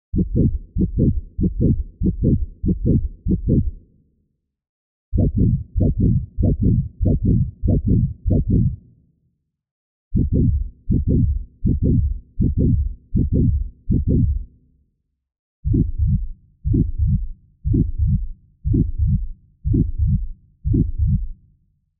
SOS Tutorial 268 iZotope RX - Alien Herzschlag SFX
Bei Klangbeispiel 01 handelt es sich um eine unbearbeitete Tonaufnahme von Meeresrauschen. Klangbeispiel 02 sind die daraus erzeugten Herzschlag SFX.
Außerdem hast du mit der hier vorgestellten Methode wesentlich mehr Flexibilität und kannst wie in Klangbeispiel 02 schnell und einfach mehrere verschiedene Versionen erstellen.
Abschließend haben wir die Herzschlag SFX mit einem Kompressor bearbeitet und eine Prise Nachhall hinzugefügt (2).